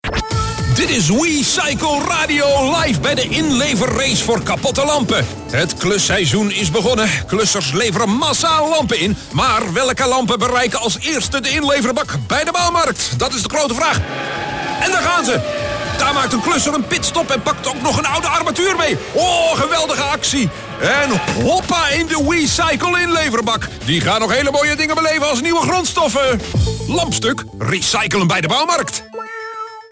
Radiocommercial